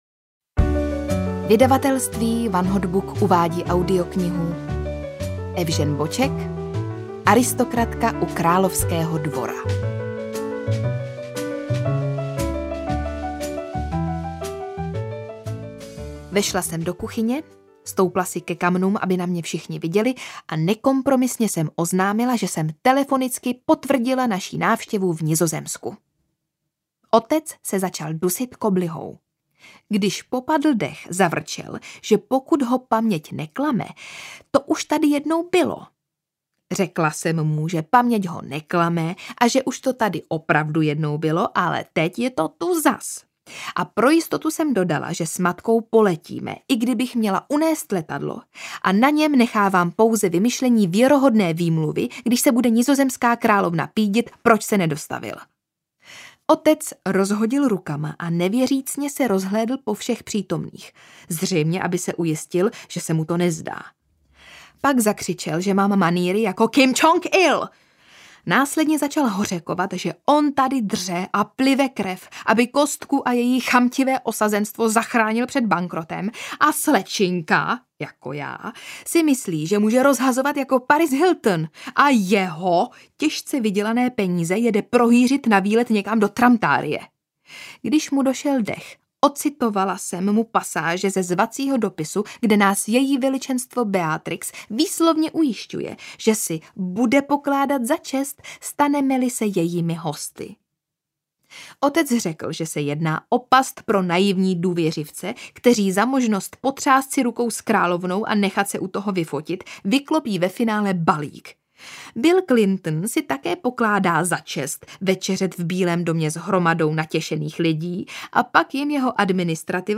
Aristokratka u královského dvora audiokniha
Ukázka z knihy